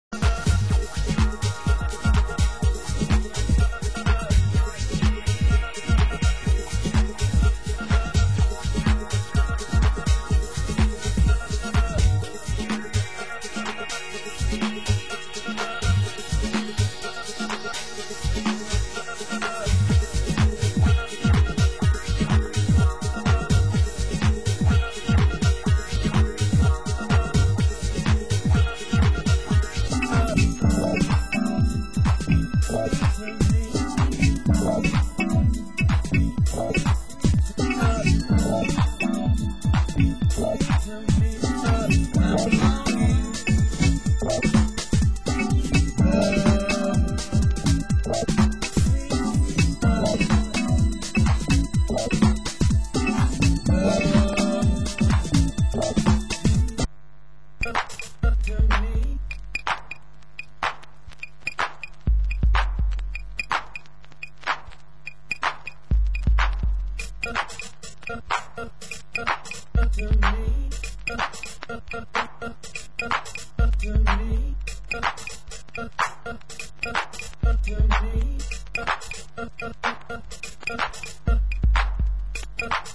Genre: Electronica